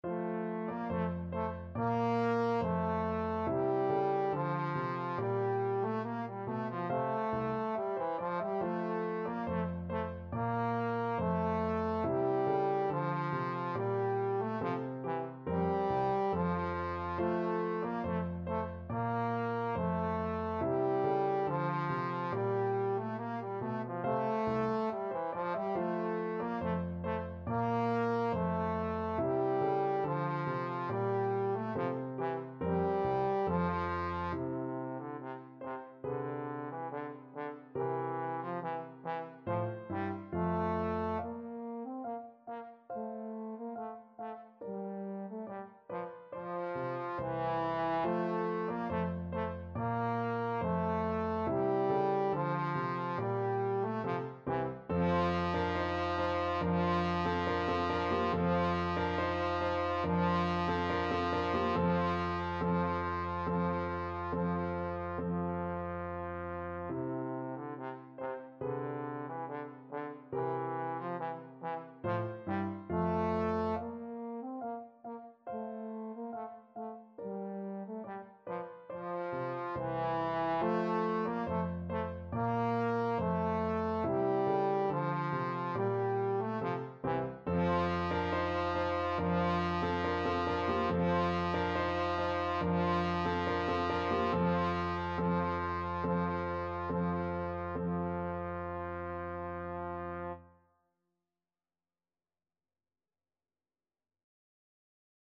Trombone
2/4 (View more 2/4 Music)
F major (Sounding Pitch) (View more F major Music for Trombone )
Classical (View more Classical Trombone Music)